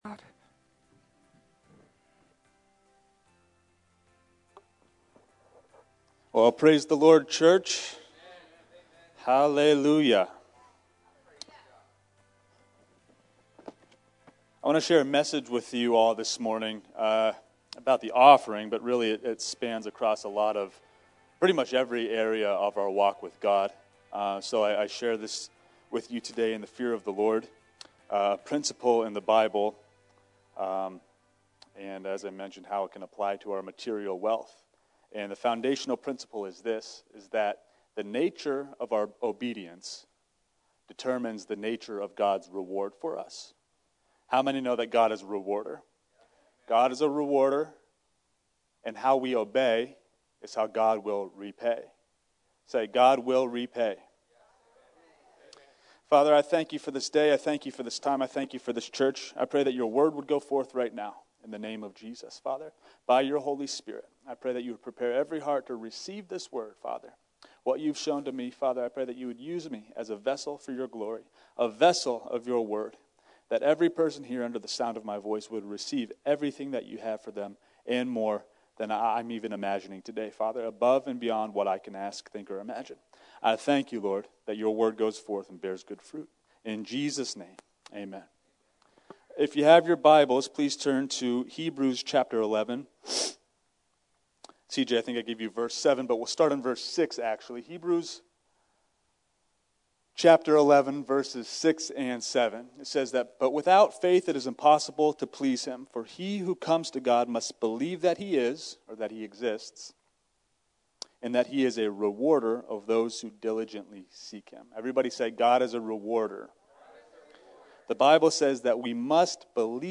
Sermons | New Freedom Church